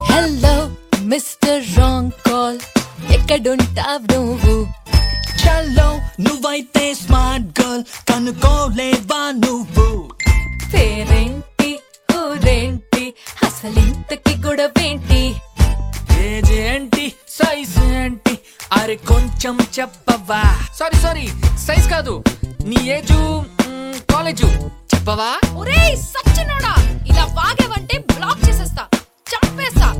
high-energy desi-style ringtone with catchy beats.